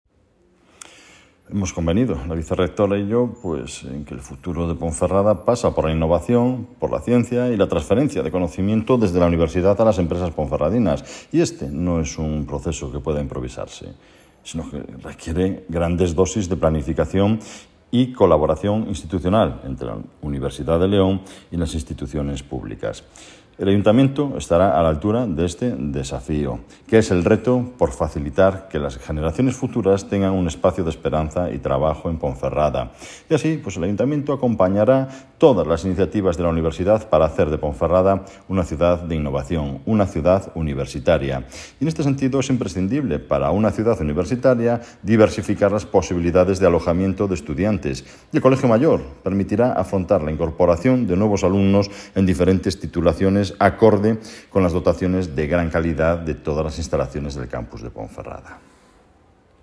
(Audionoticia)